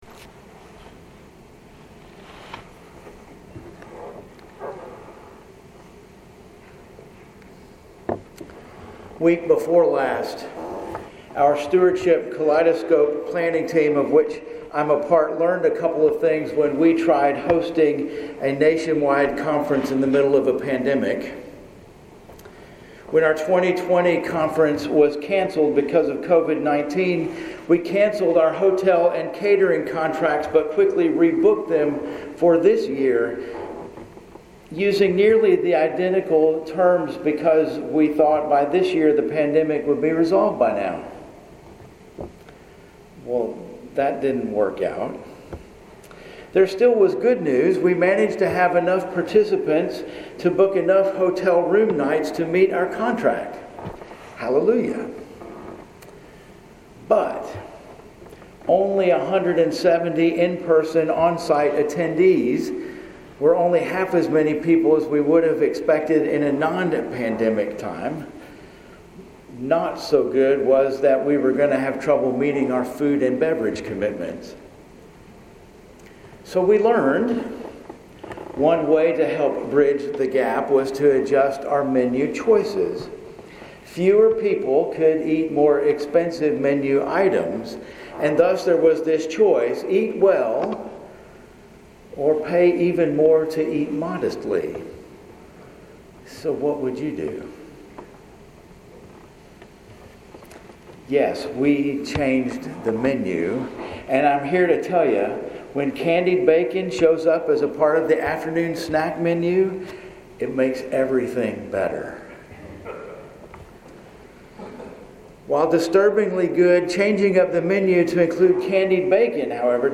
Sermons at First Presbyterian Church El Dorado, Arkansas